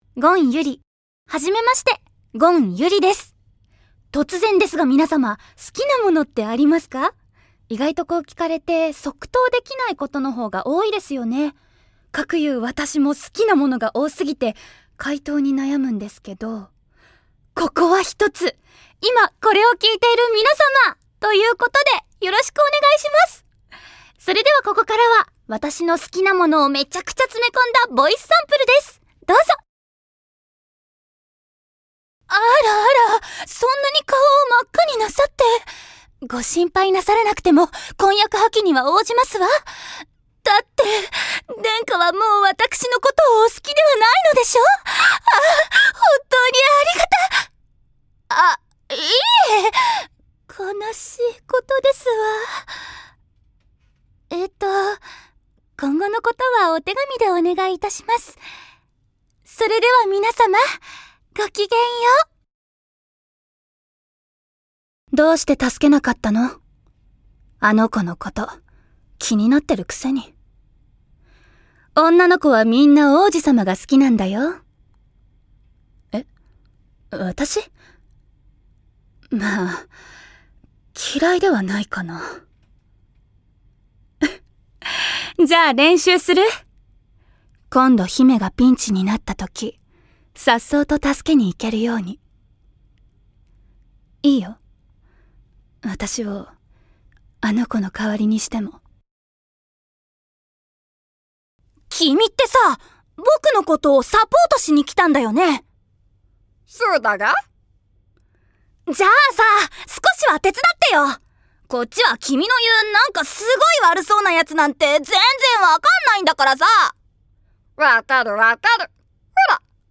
方言　 ： 大阪弁
◆台詞